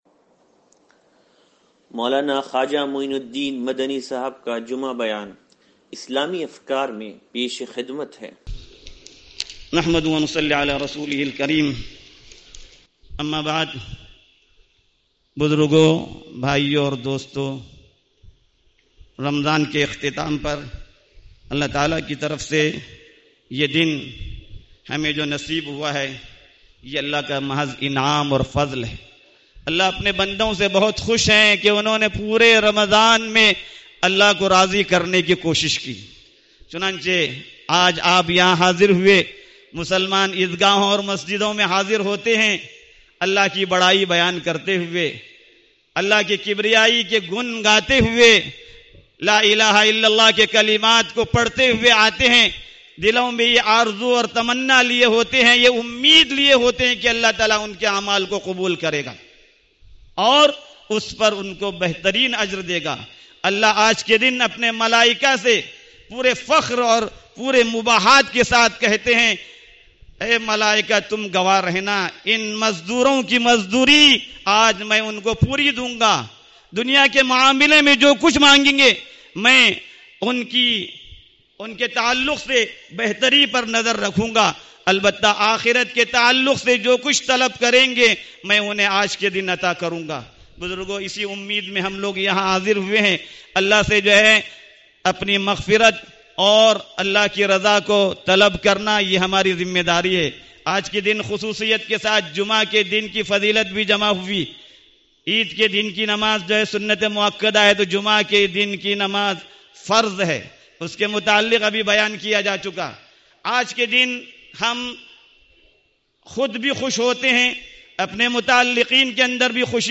عید الفطر بیان